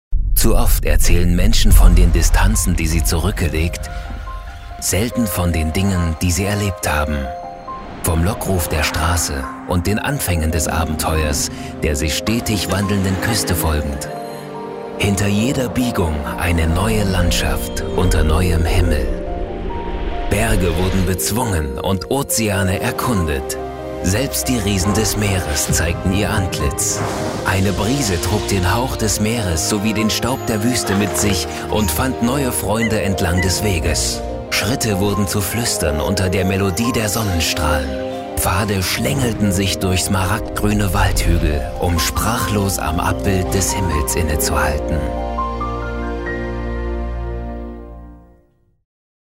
Professioneller Sprecher - Schnelle Bearbeitung - Eigenes Studio
Kein Dialekt
Sprechprobe: Sonstiges (Muttersprache):
Doku Garden Route.mp3